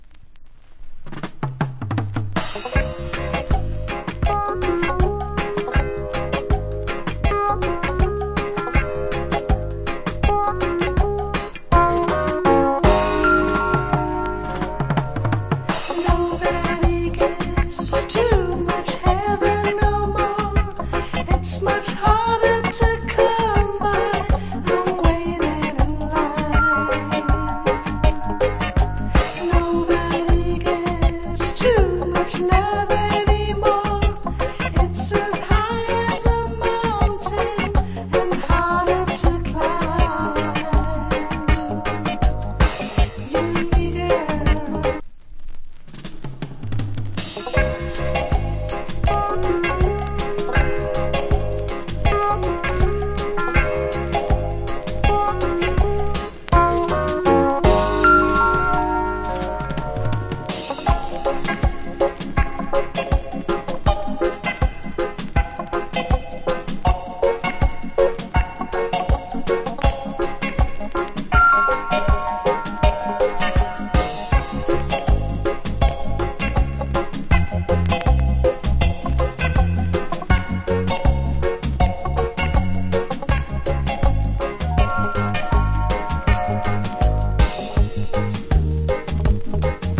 Nice JA Lovers Vocal.